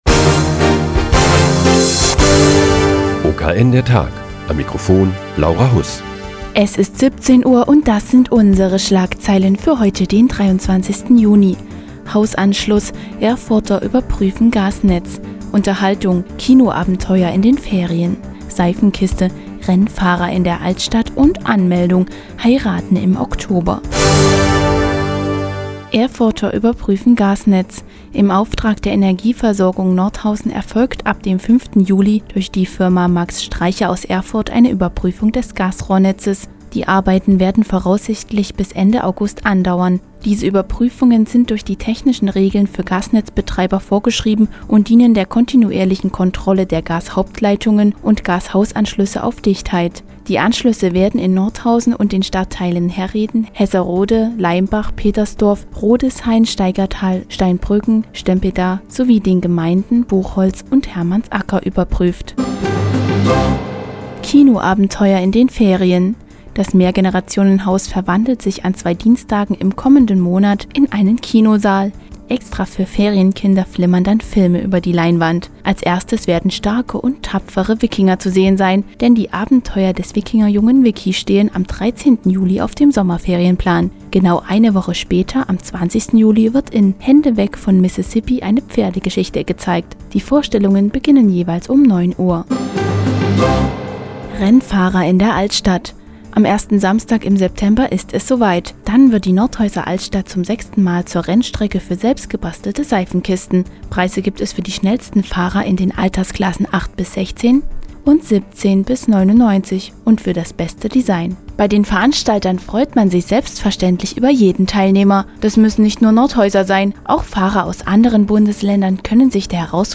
Die tägliche Nachrichtensendung des OKN ist nun auch in der nnz zu hören. Heute geht es um eine Überprüfung des Gasrohrnetzes in Nordhausen und Umgebung und das Seifenkistenrennen Anfang September.